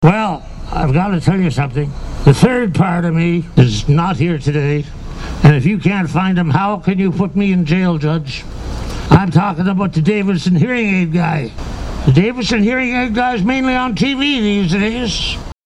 The Third Annual Arnprior Lions Jail and Bail had more comedy and drama than ever before, as the fundraiser nestled into the Giant Tiger Parking Lot Saturday.
But among the incarcerees who stole the show, it was Valley legend Wayne Rostad with his talking dummy alter ego who stood out.